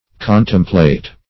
Contemplate \Con"tem*plate\ (?; 277), v. t. [imp.